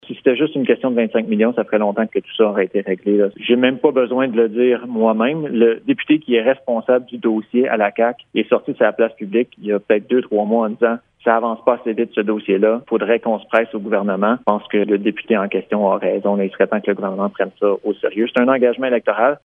Selon André Fortin, député de Pontiac, ce montant ne sera pas suffisant pour offrir un accès au réseau cellulaire à tous les Québécois :